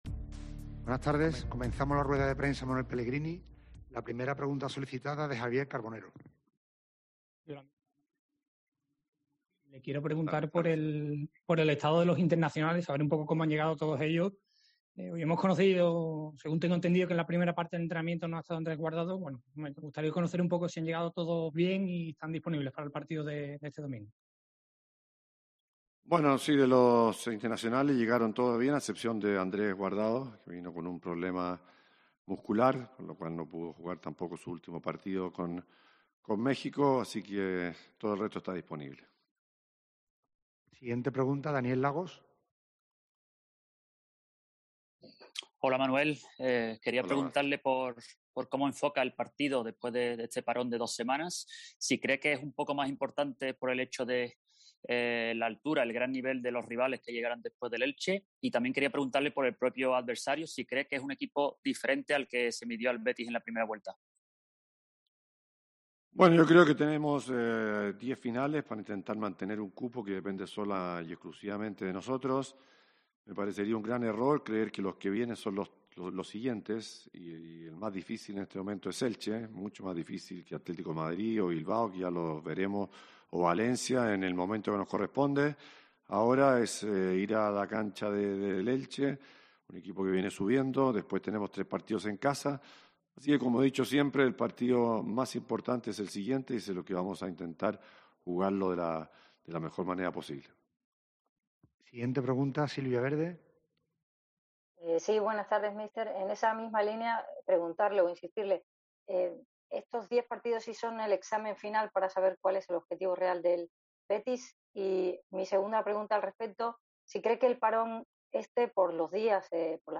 RUEDA DE PRENSA DE MANUEL PELLEGRINI PREVIA AL CHOQUE ENTE EL ELCHE